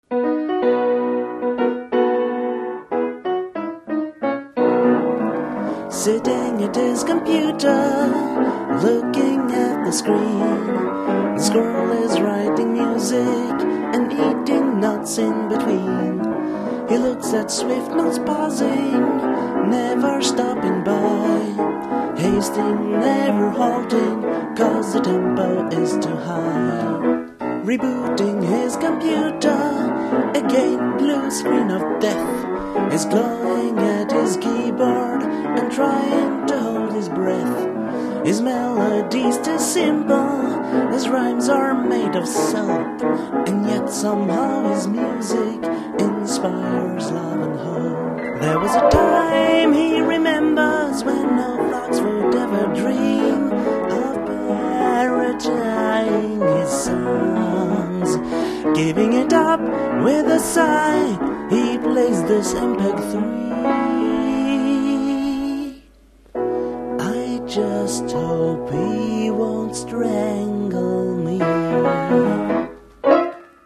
Style: Boogie-woogie